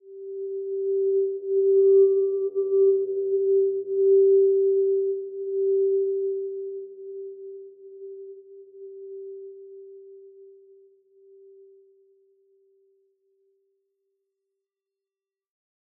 healing-soundscapes
Simple-Glow-G4-f.wav